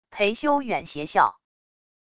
裴修远邪笑wav音频